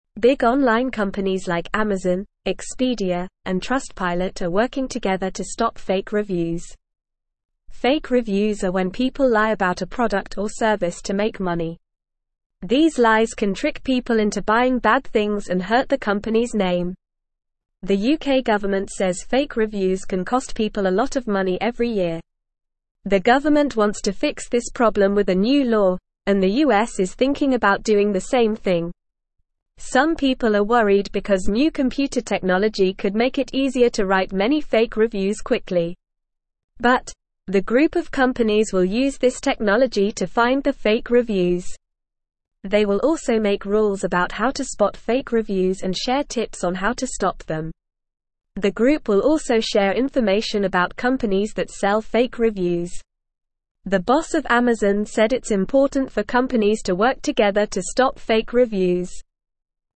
Normal
English-Newsroom-Beginner-NORMAL-Reading-Big-Companies-Join-Forces-to-Stop-Fake-Reviews.mp3